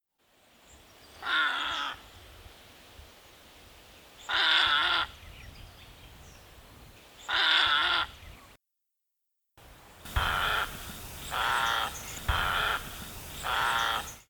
White-faced Heron calls:
white-faced-heron.mp3